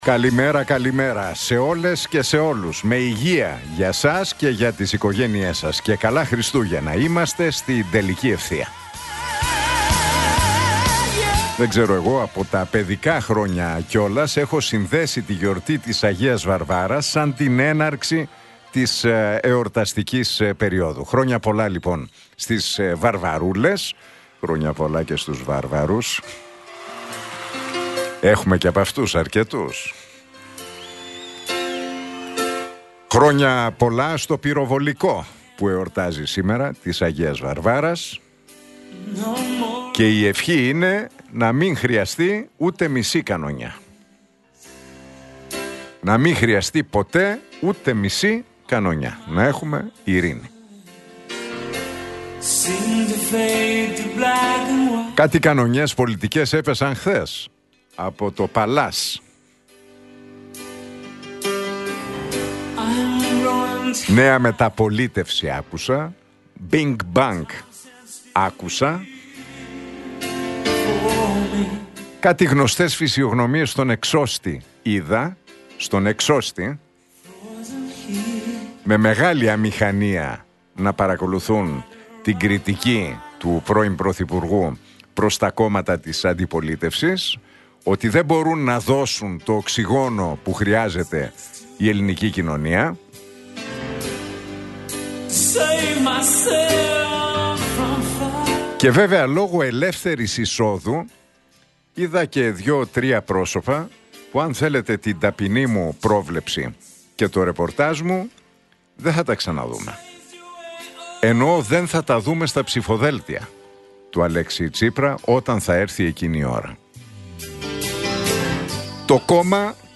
Ακούστε το σχόλιο του Νίκου Χατζηνικολάου στον ραδιοφωνικό σταθμό Realfm 97,8, την Πέμπτη 4 Δεκεμβρίου 2025.